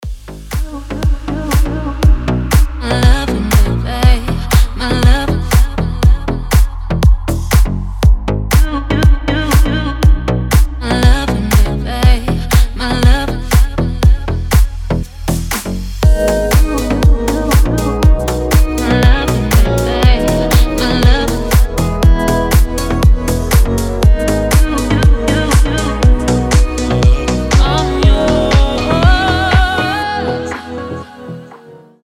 • Качество: 320, Stereo
красивые
deep house
Vocal House
Дипчик про любовь